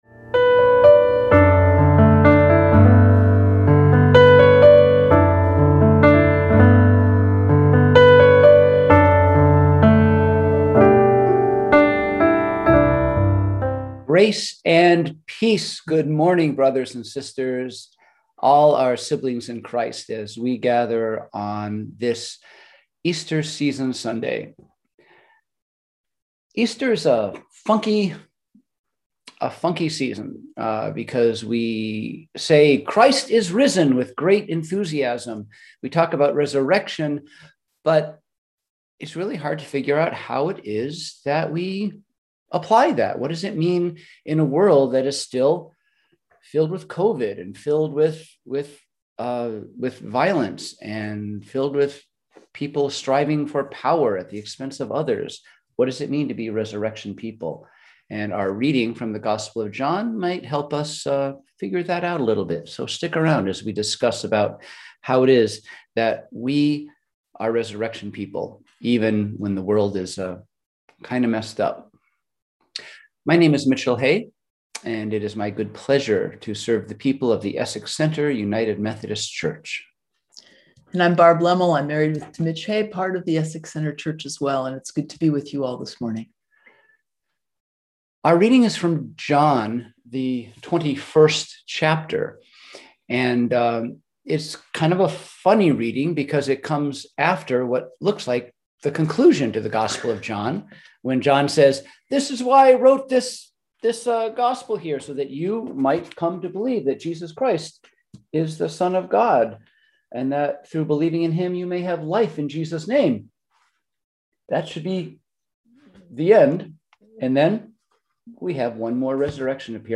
We held virtual worship on Sunday, April 25, 2021 at 10:00AM!